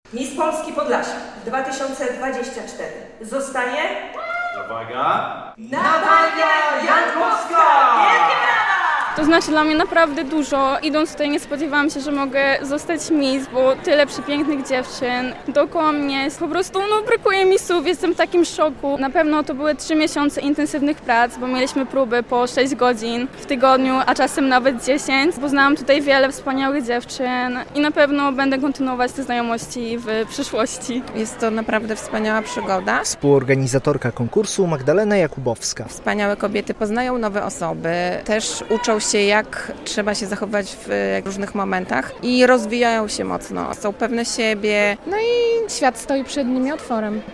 W piątek (7.06) w Białymstoku na scenie Opery i Filharmonii Podlaskiej wystąpiły finalistki konkursu Miss Polski Podlasia 2024.
relacja